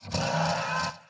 Minecraft Version Minecraft Version 1.21.5 Latest Release | Latest Snapshot 1.21.5 / assets / minecraft / sounds / mob / horse / skeleton / idle3.ogg Compare With Compare With Latest Release | Latest Snapshot